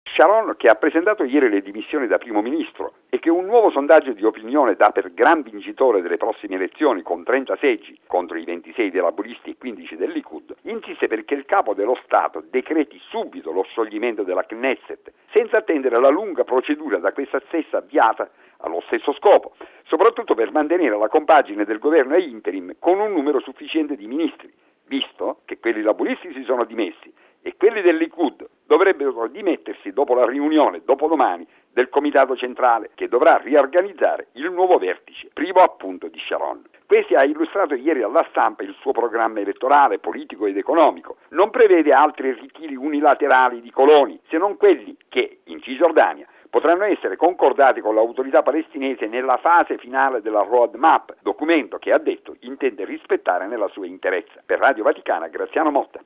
Così il presidente israeliano Katzav al termine dell’incontro di ieri col premier Sharon, che ha esplicitamente chiesto lo scioglimento della legislatura e ha fondato un nuovo partito. Il servizio